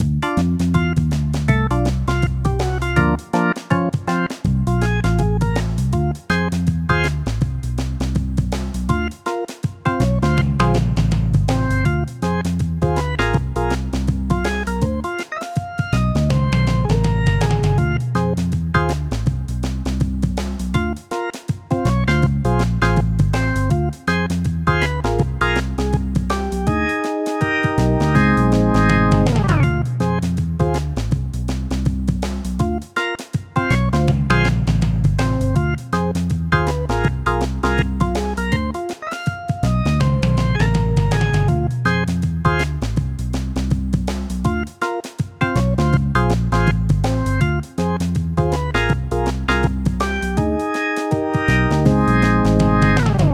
70's organ funk_0.ogg